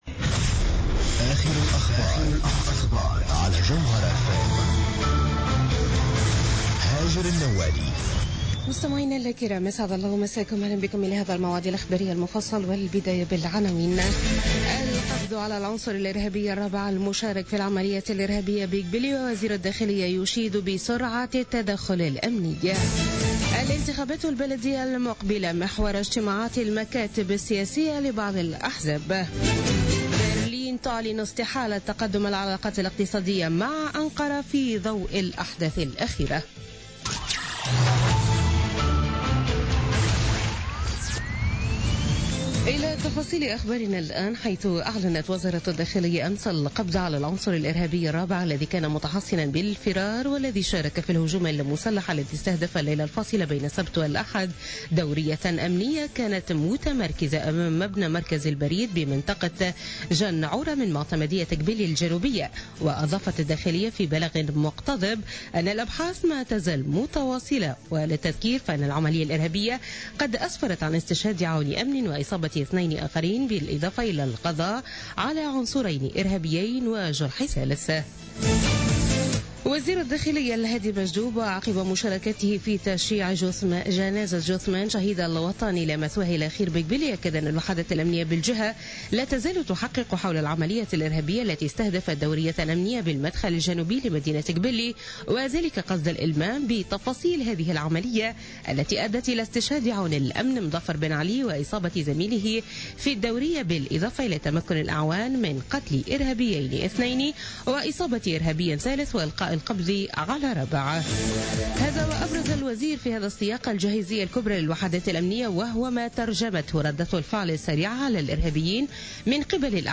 نشرة أخبار منتصف الليل ليوم الإثنين 13 مارس 2017